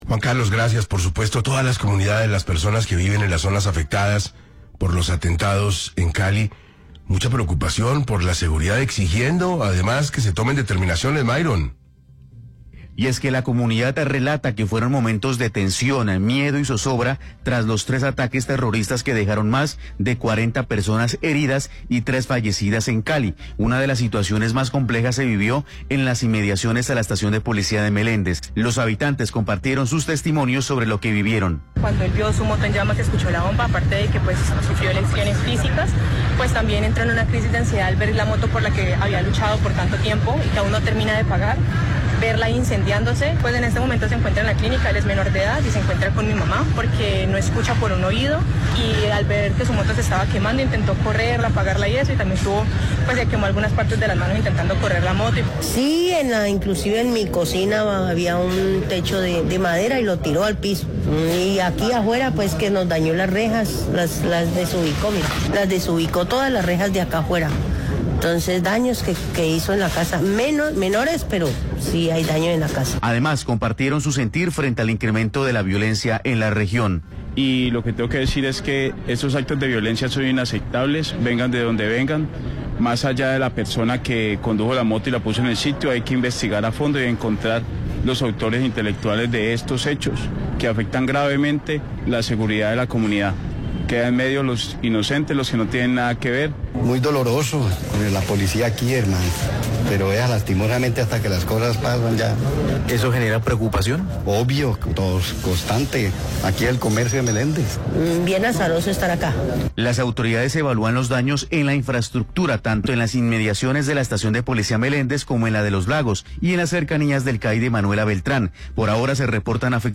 En entrevista con 6AM de Caracol Radio, la mandataria regional exigió mayor presencia del Estado y respaldo efectivo de las Fuerzas Armadas para enfrentar la creciente amenaza terrorista.